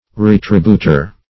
Retributer \Re*trib"u*ter\, n. One who makes retribution.